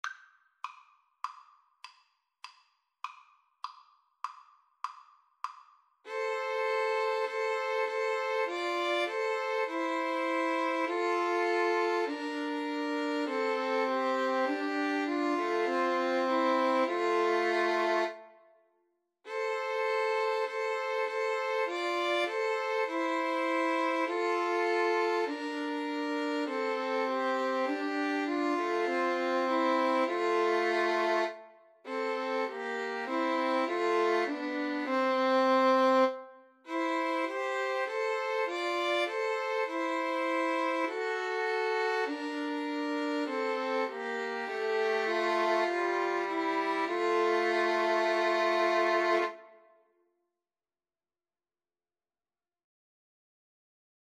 Violin 1Violin 2Cello
4/4 (View more 4/4 Music)
F major (Sounding Pitch) (View more F major Music for 2-Violins-Cello )